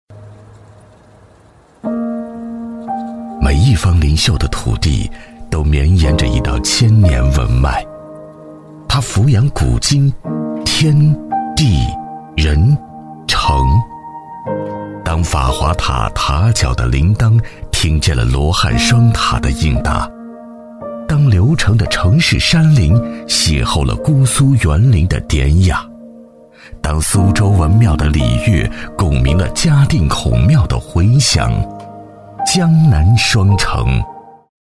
男42-地域解说【儒风之行（磁性）】
男42-磁性质感 质感磁性
男42-地域解说【儒风之行（磁性）】.mp3